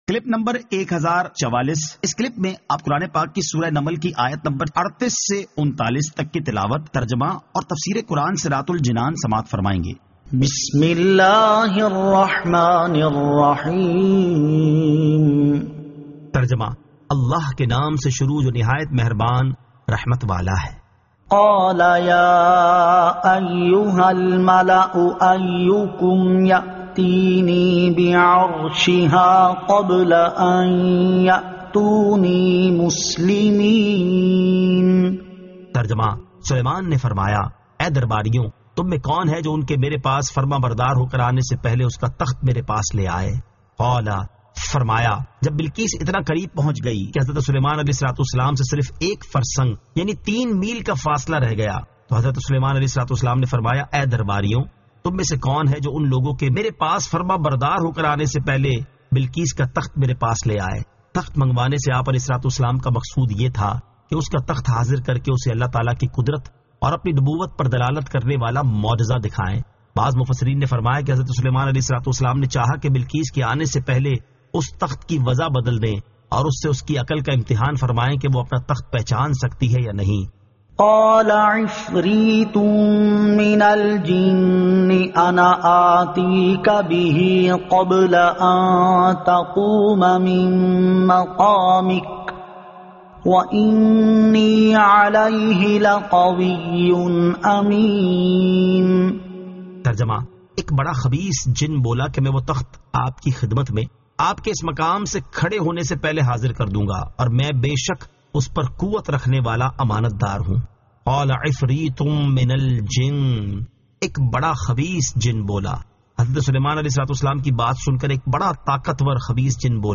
Surah An-Naml 38 To 39 Tilawat , Tarjama , Tafseer